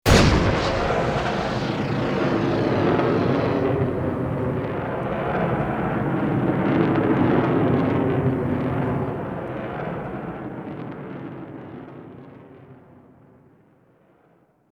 На этой странице собраны реалистичные звуки пуль — от одиночных выстрелов до очередей.
Снайперская пуля мчится издалека